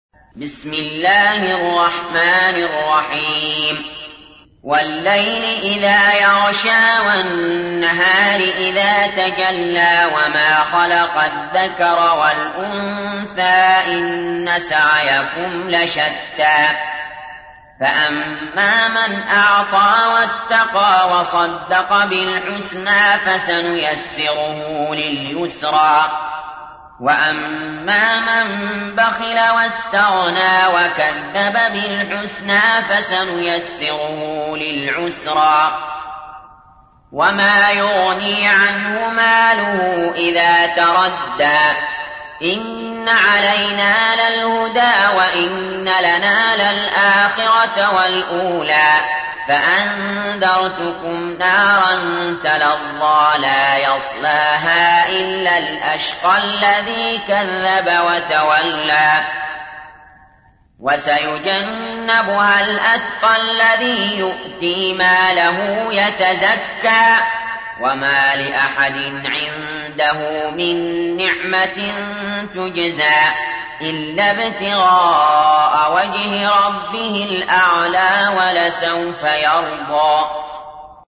تحميل : 92. سورة الليل / القارئ علي جابر / القرآن الكريم / موقع يا حسين